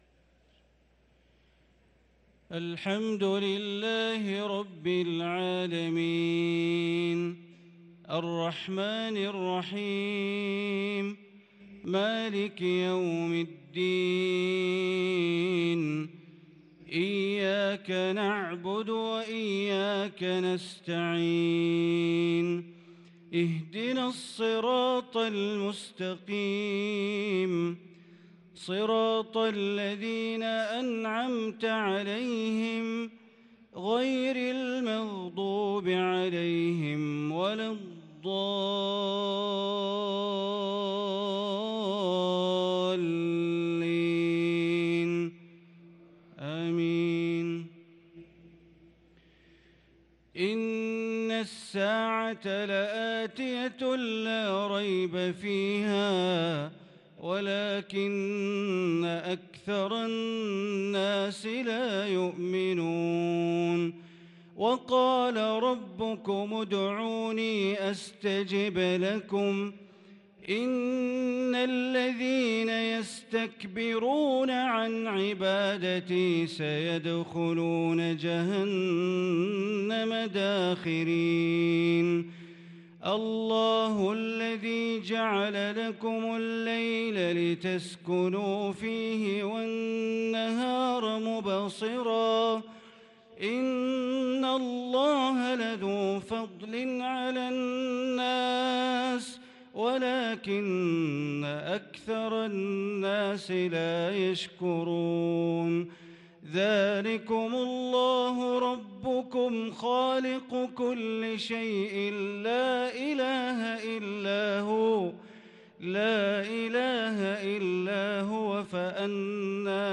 صلاة العشاء للقارئ بندر بليلة 3 رمضان 1443 هـ